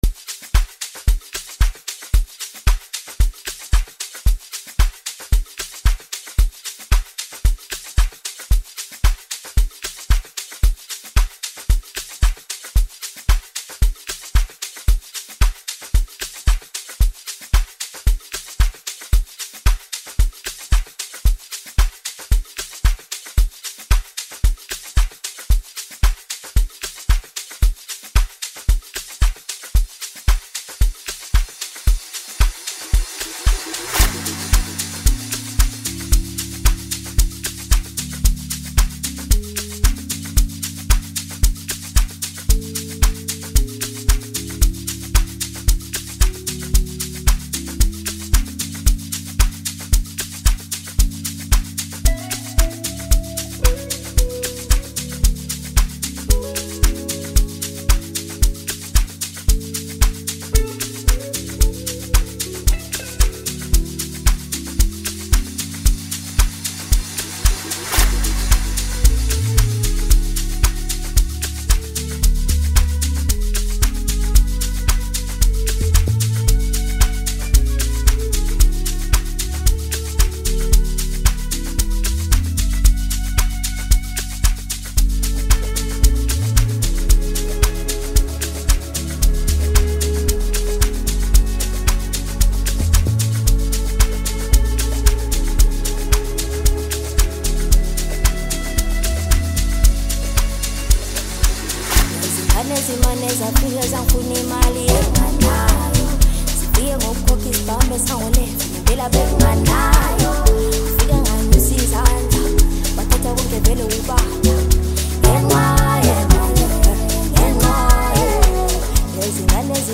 Home » Amapiano » Album?EP